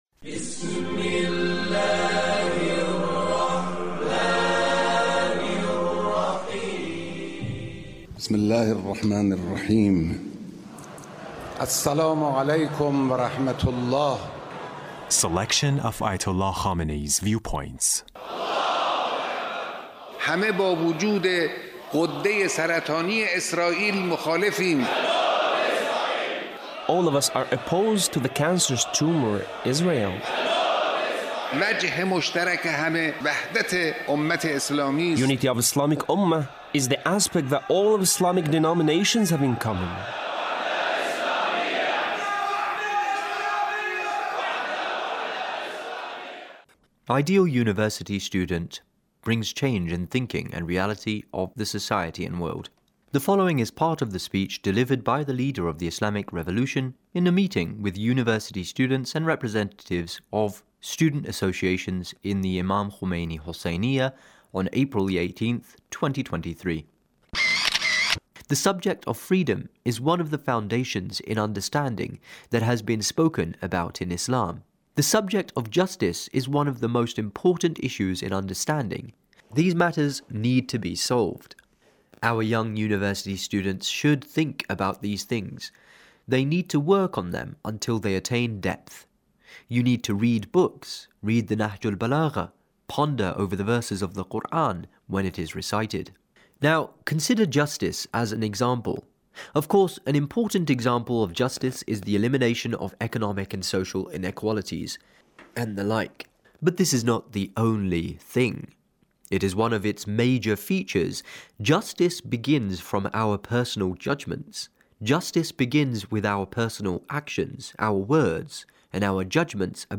Leader's Speech with University Student